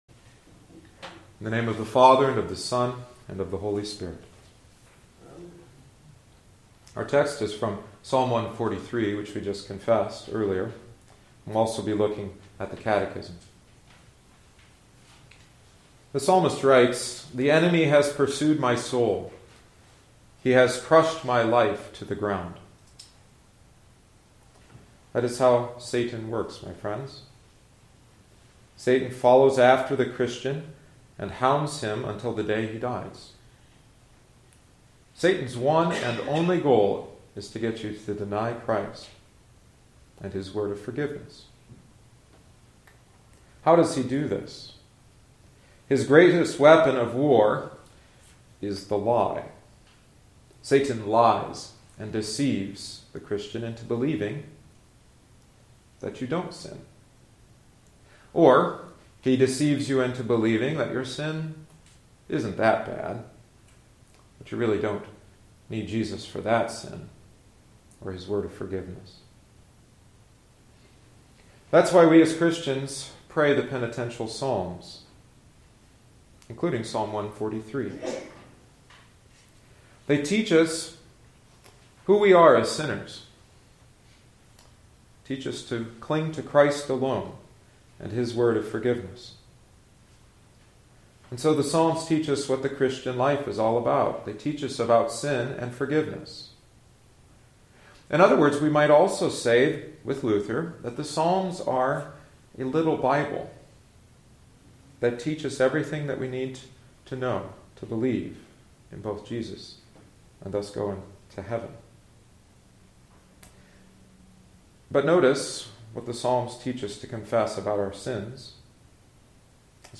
Sermon on Psalm 143